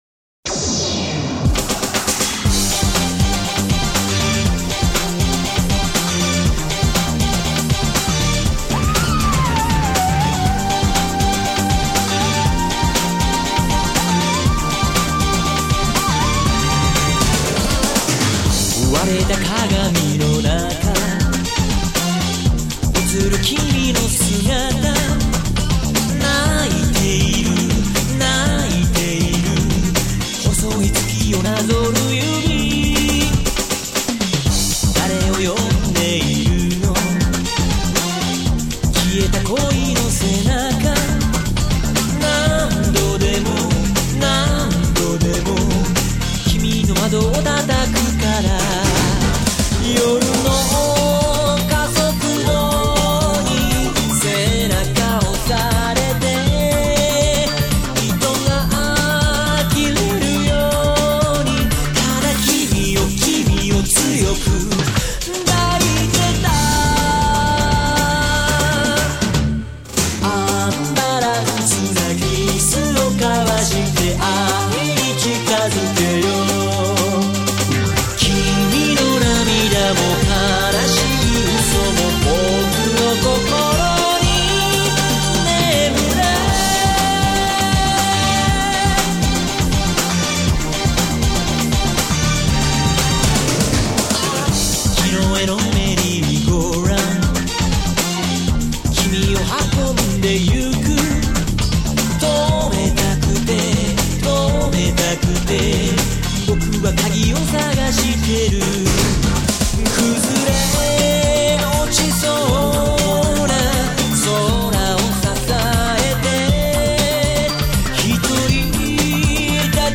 Terza sigla di Chiusura